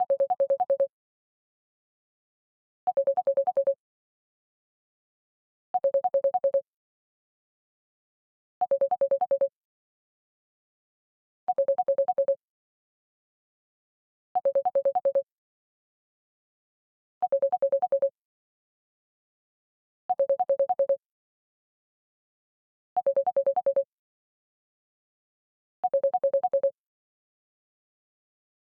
• WhatsApp has added a new ringtone when you receive a group call:
GroupCall_Ringtone_IOS.ogg